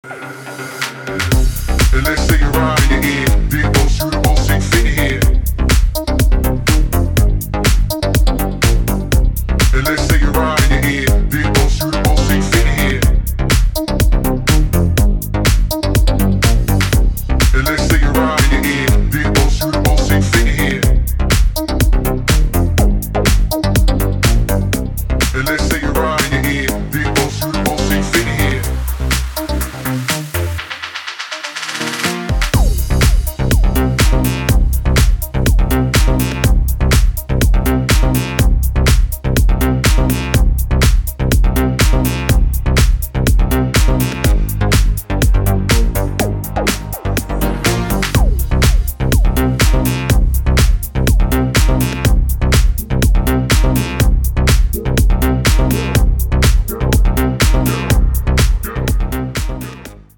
• Качество: 320, Stereo
deep house
nu disco
Indie Dance
Indie Dance / Nu Disco